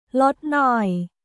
ロッ ノイ